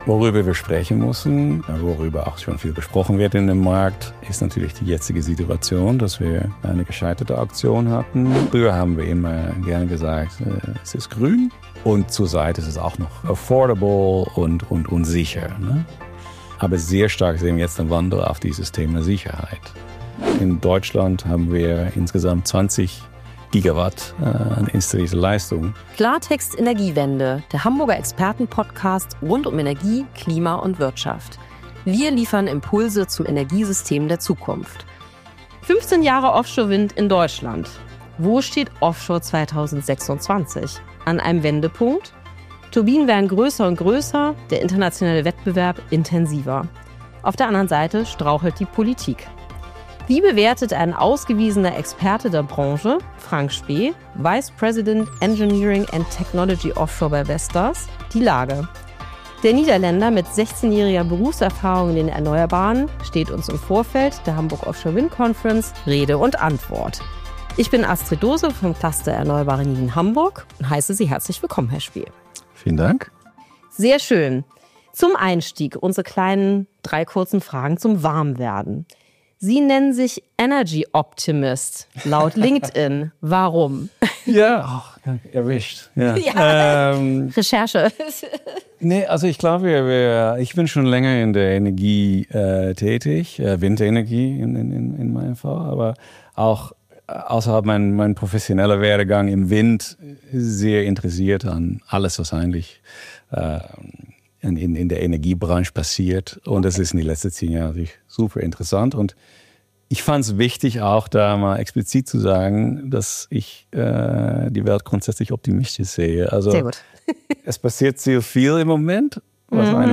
Ein Gespräch über Chancen, Risiken und die Frage, was jetzt passieren muss, damit Offshore-Wind seine zentrale Rolle in der Energiewende erfüllen kann.